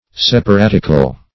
Search Result for " separatical" : The Collaborative International Dictionary of English v.0.48: Separatical \Sep`a*rat"ic*al\, a. Of or pertaining to separatism in religion; schismatical.